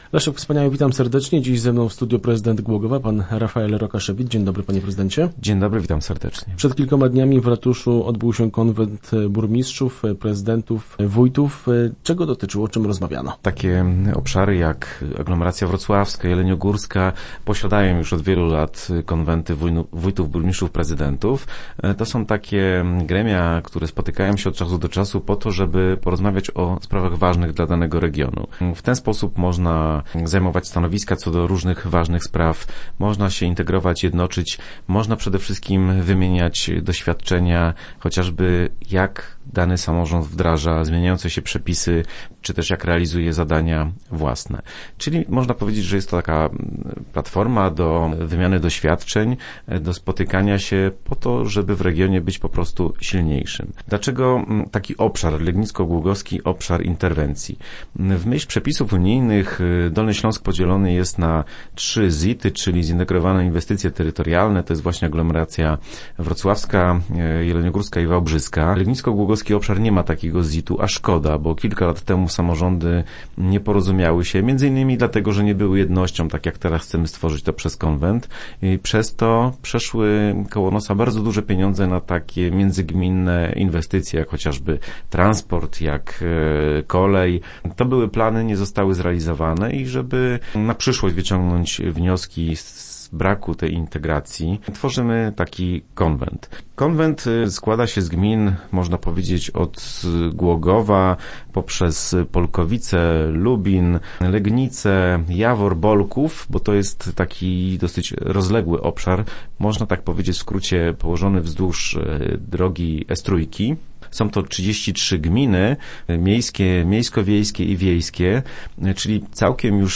Powołano na nim Legnicko-Głogowski Obszar Interwencji, w skład którego weszły 33 gminy. O planach na najbliższe miesiące mówił w radiowym studiu prezydent Głogowa Rafael Rokaszewicz.